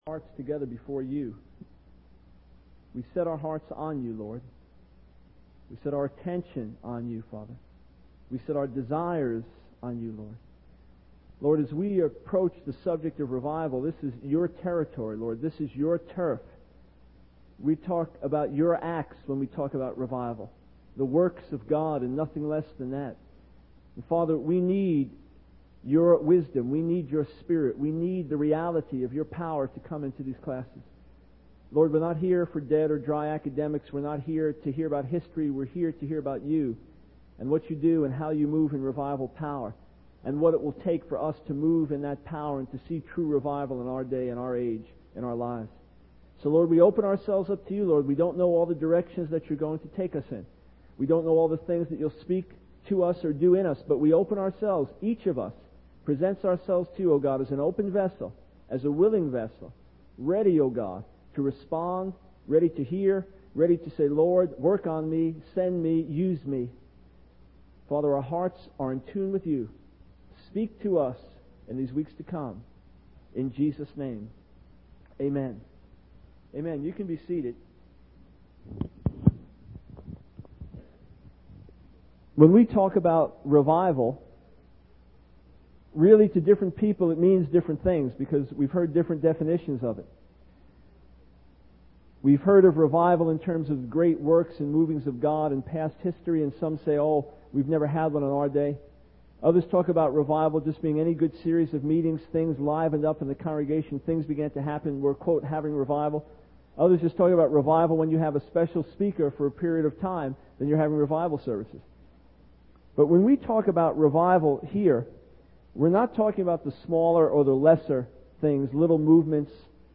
In this sermon, the speaker emphasizes the importance of recognizing what we don't know.